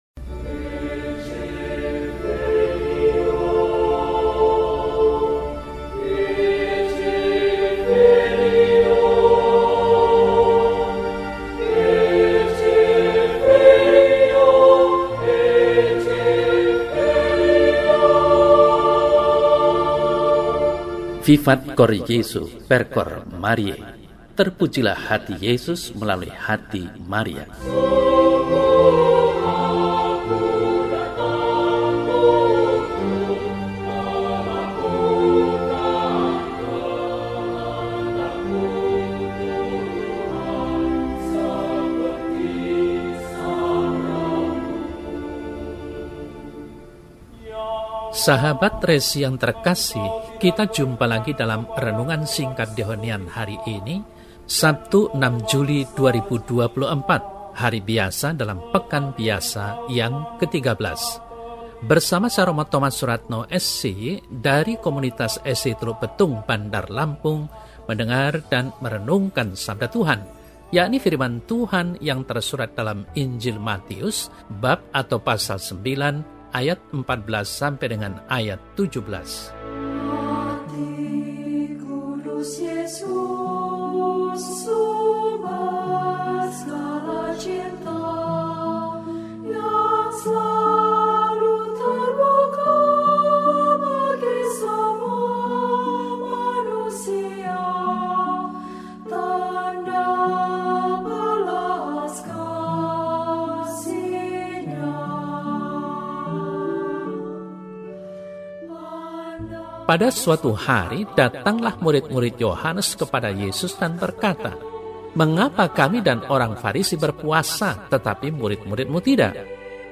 Sabtu, 06 Juli 2024 – Hari Biasa Pekan XIII – RESI (Renungan Singkat) DEHONIAN